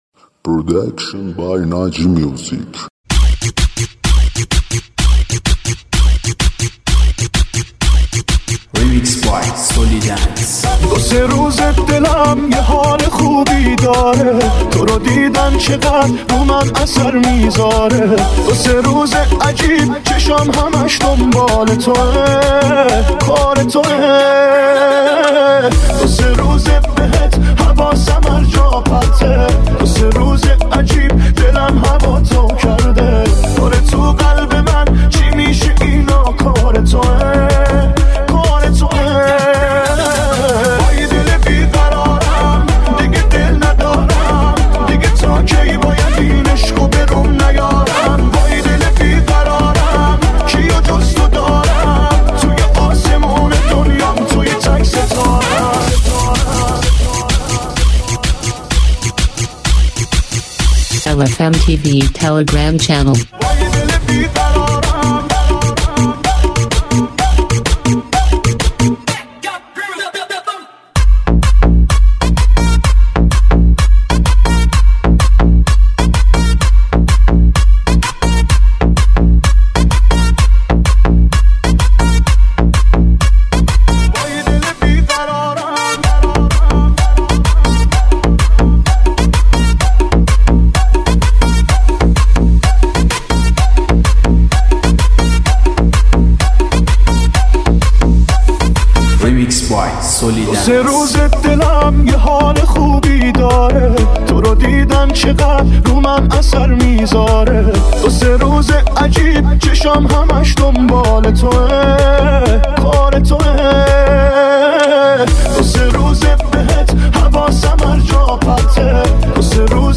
آهنگ شاد تریبال مخصوص پارتی و رقص
ریمیکس های شاد تریبال